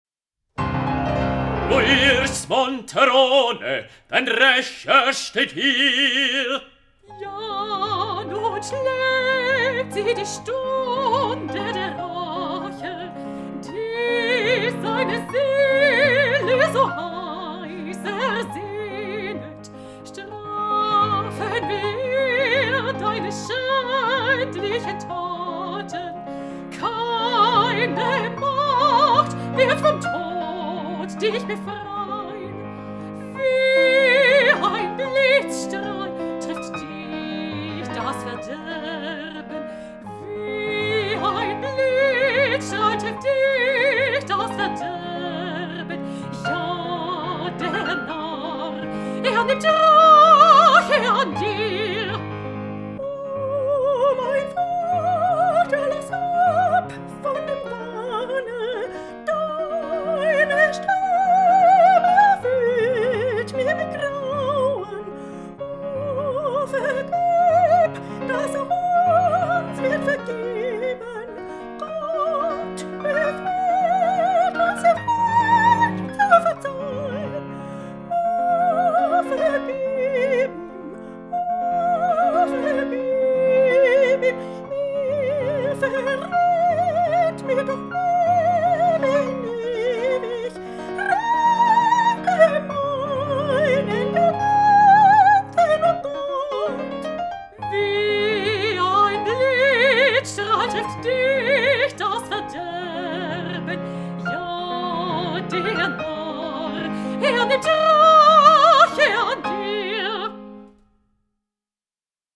Ja, nun schlägt sie, die Stunde der Rache (Chor)
09_ja_nun_schlaegt_sie_die_stunde_der_rache_chor.mp3